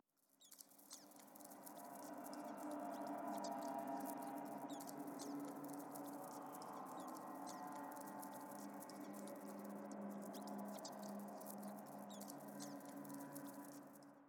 环境音
05_诡异通用.wav